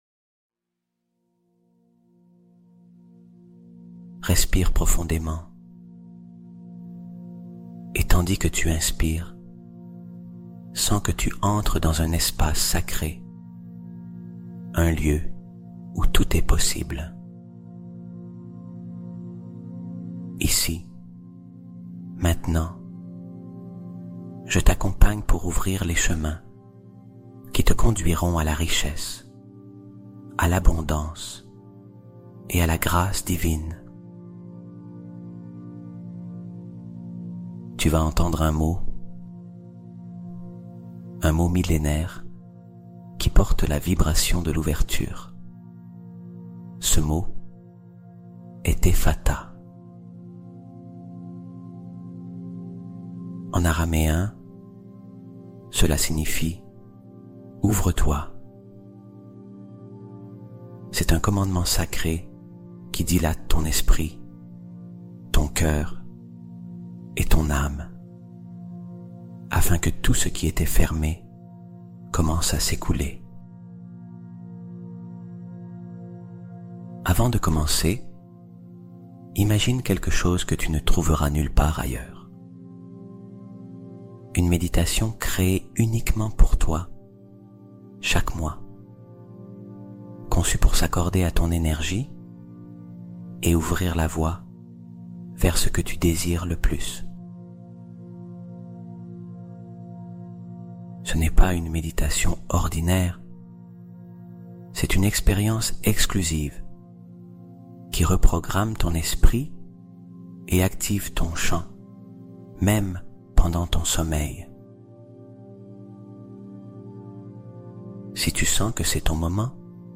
EFFATÁ 888 Hz ✦ La richesse que l'Univers t'a RÉSERVÉE arrive cette nuit